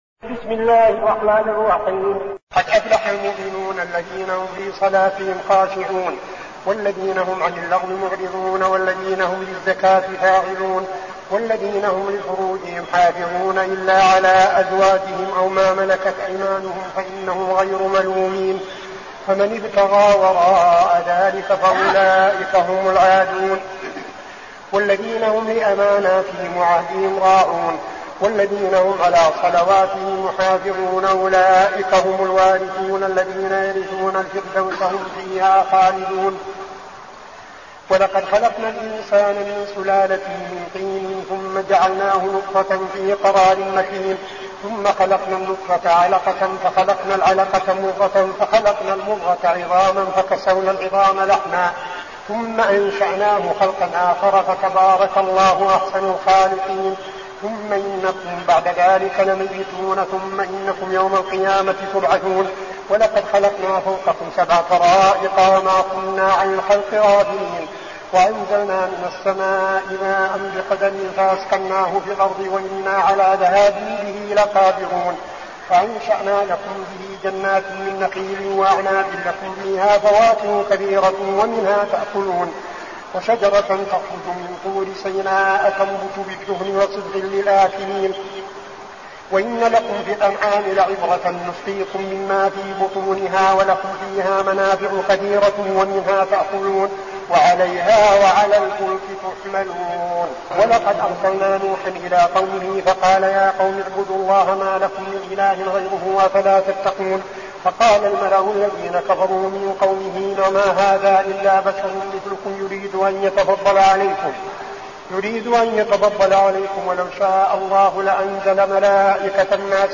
المكان: المسجد النبوي الشيخ: فضيلة الشيخ عبدالعزيز بن صالح فضيلة الشيخ عبدالعزيز بن صالح المؤمنون